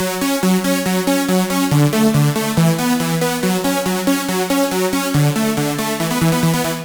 VTDS2 Song Kit 11 Rap When I Come Around Synth EuroLead.wav